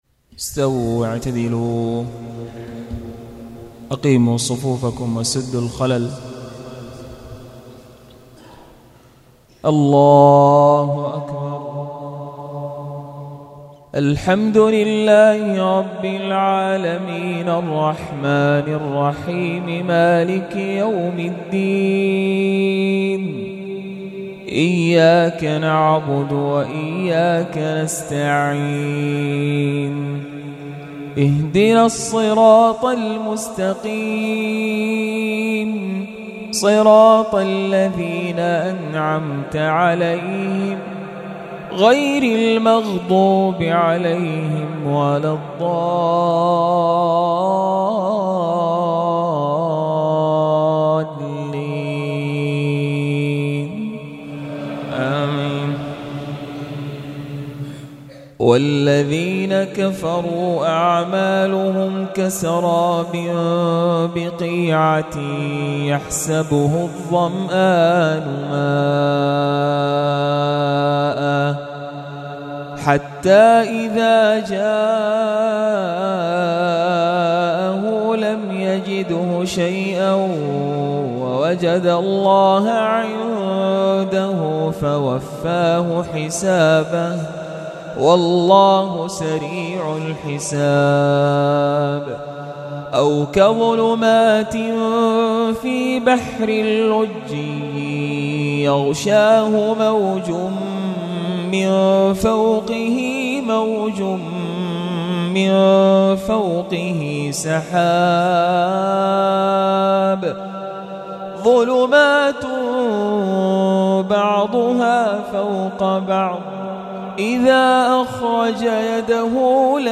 الرئيسية تلاوات خاشعة ما تيسر من سورة النور لعام 1439هـ